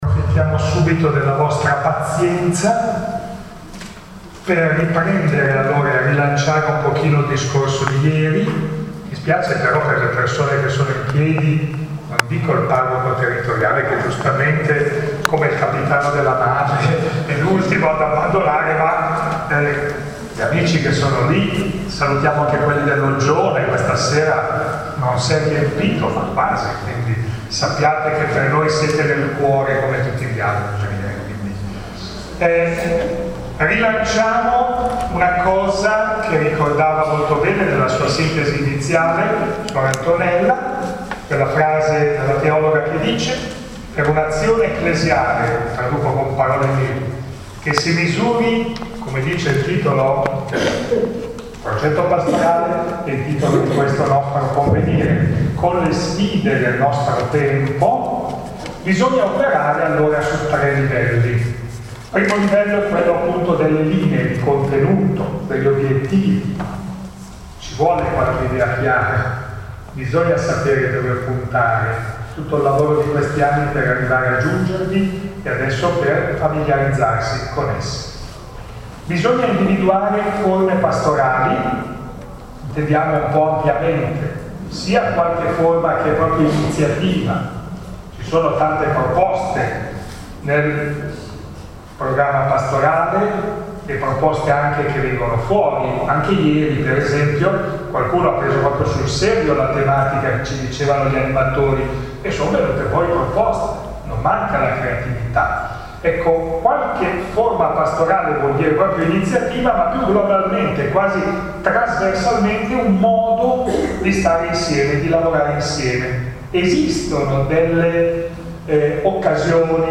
al Convegno ecclesiale diocesano (seconda giornata)